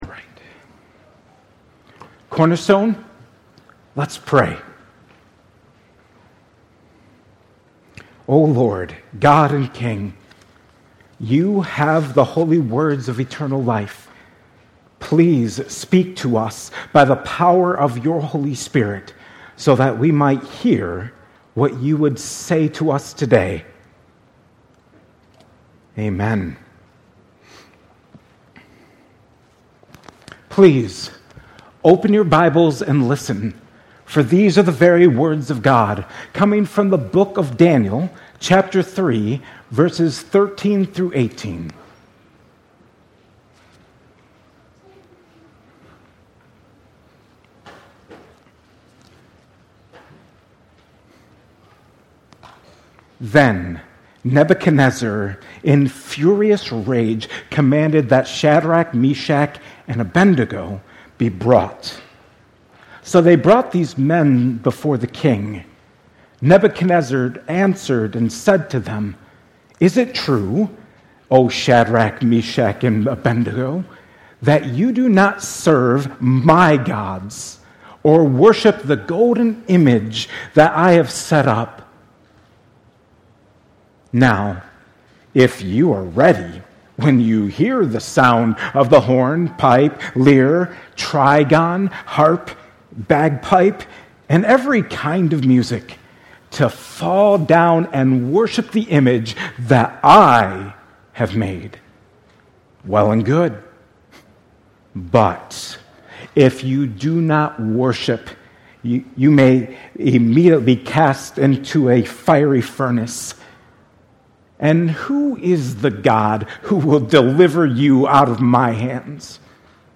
at Cornerstone Church